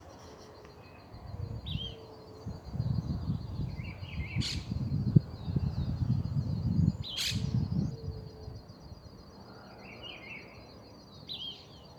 Fiofío Pico Corto (Elaenia parvirostris)
Nombre en inglés: Small-billed Elaenia
Condición: Silvestre
Certeza: Observada, Vocalización Grabada